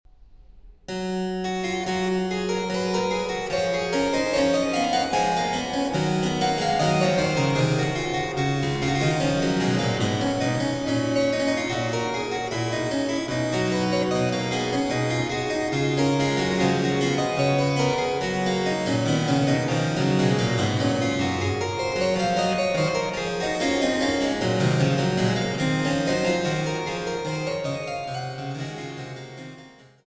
Meisterwerke der französischen Gambenmusik